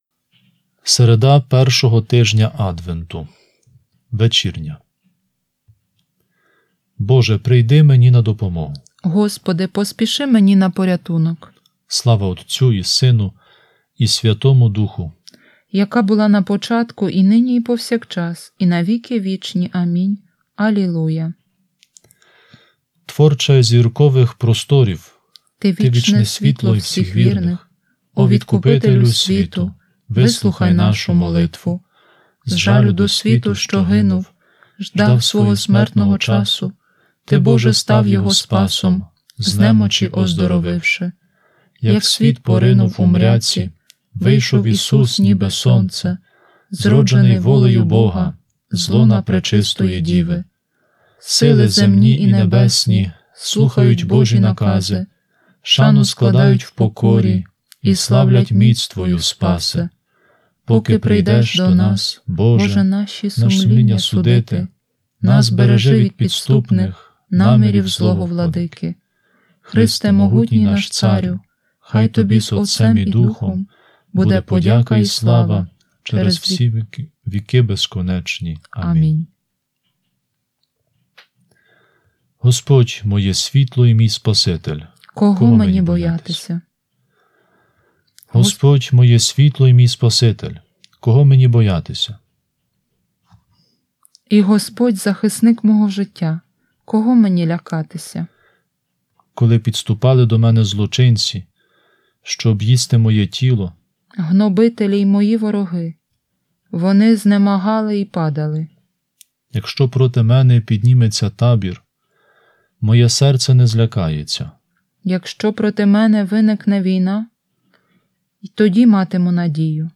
Вечірня